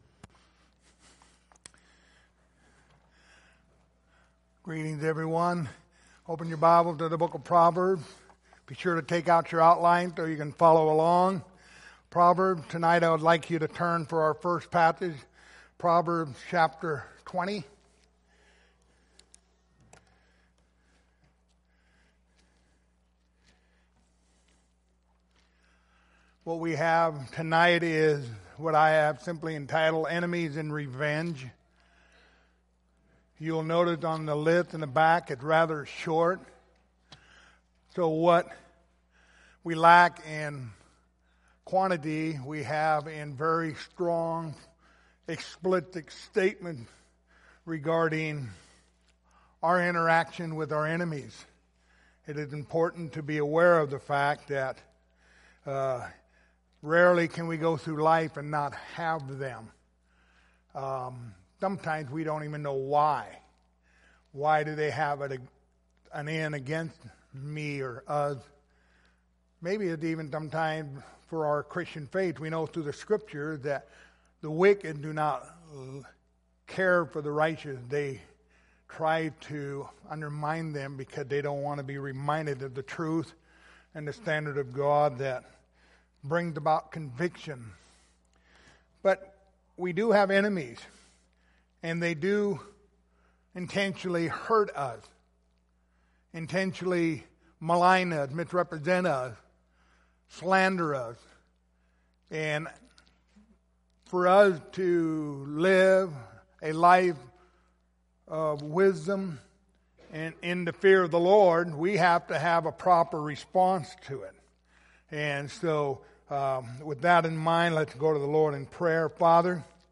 Proverbs 16:7 Service Type: Sunday Evening Topics